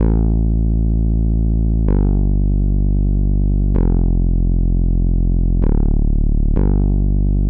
VDE 128BPM Notice Bass 1 Root A.wav